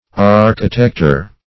Architector \Ar"chi*tec`tor\, n.